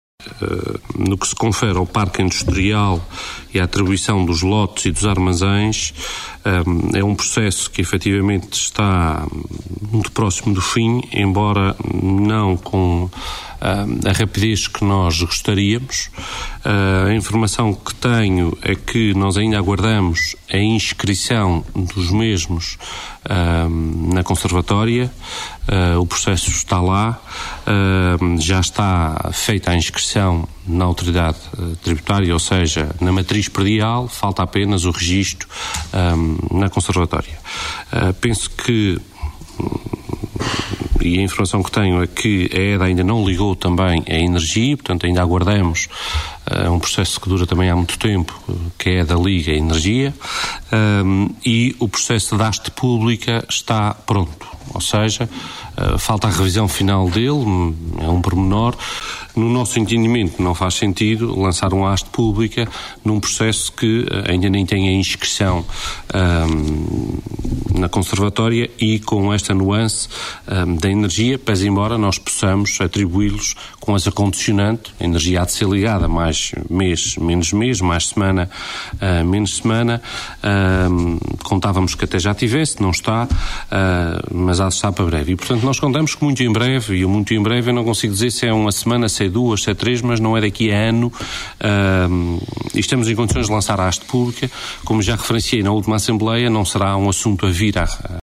Luís Silveira falava na última Assembleia Municipal de Velas, quando afirmou que o processo da atribuição dos lotes e dos armazéns está “muito próximo do fim”.